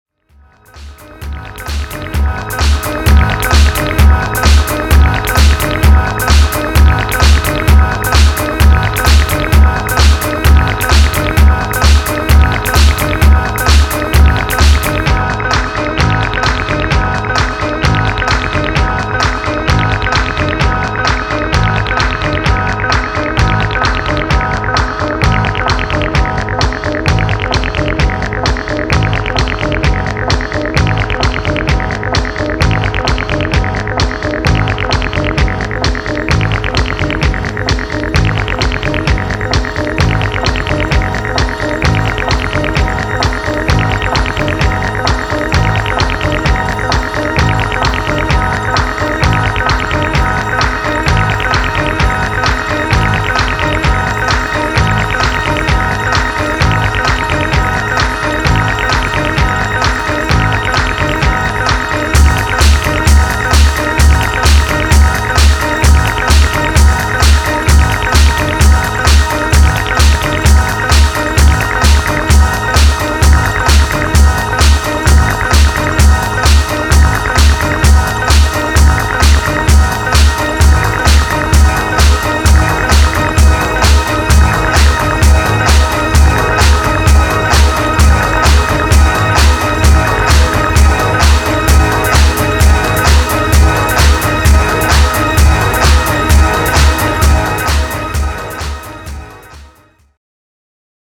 tape saturated thumping house trax
House Techno